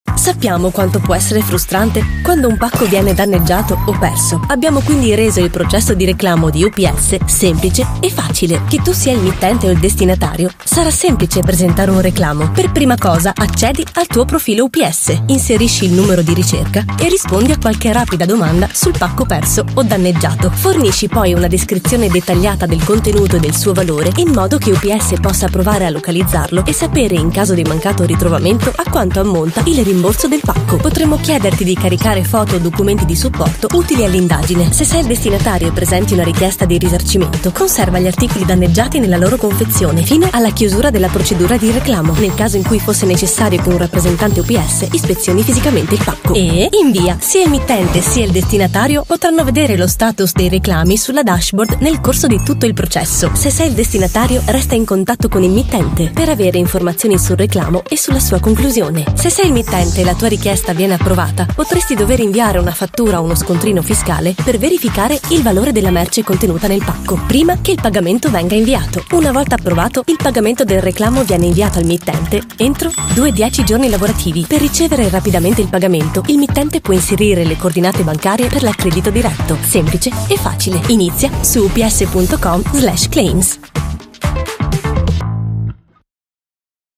Erklärvideos
Sie werden meine Stimme aufrichtig, freundlich, authentisch, hell, emotional, institutionell, frisch und energisch finden.
- Professionelle Gesangskabine